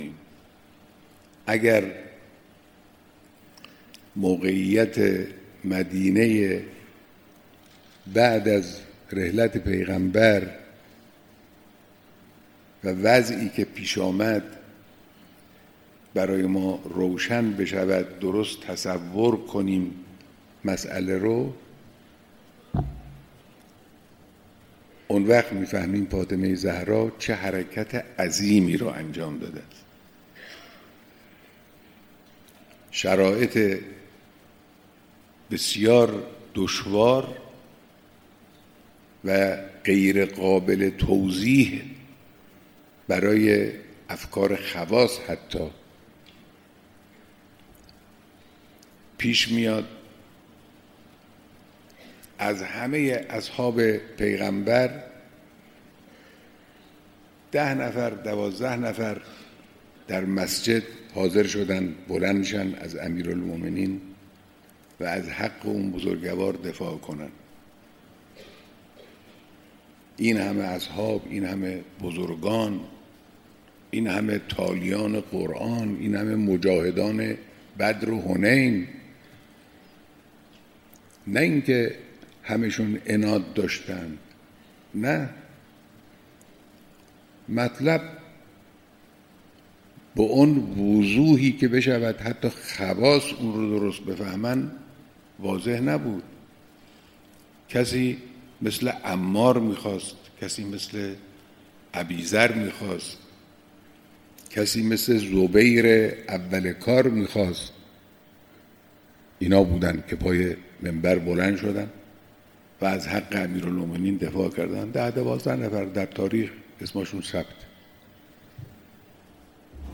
بیانات در دیدار مداحان اهل‌بیت علیهم‌السلام